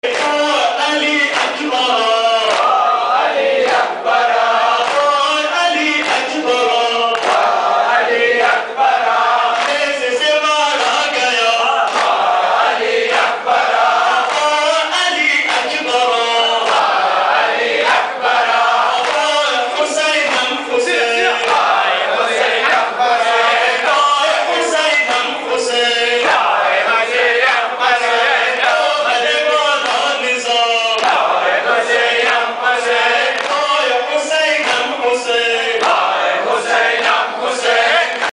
Ending Matams